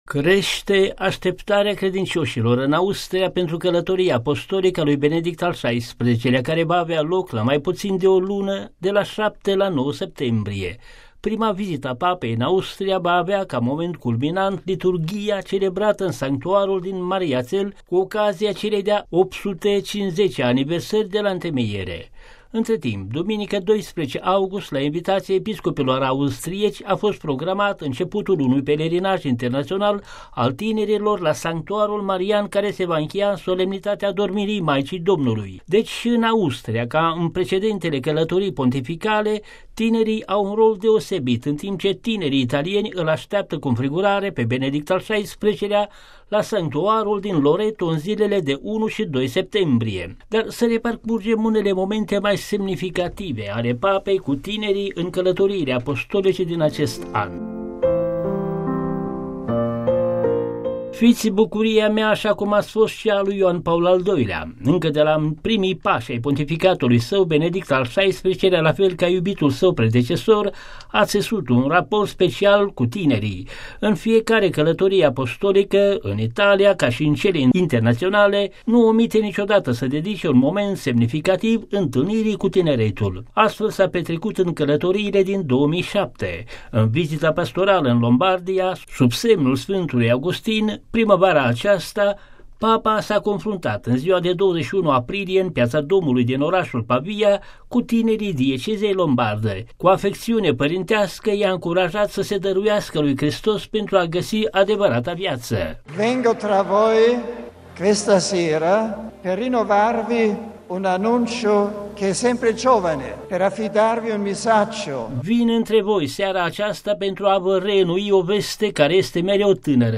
Ins - secvenţe muzicale.
Despre felul cum s-a născut ideea acestui pelerinaj, răspunde mons Franz Lackner, episcop de Graz-Seckau şi responsabil cu pastorala tineretului în cadrul episcopatului austriac: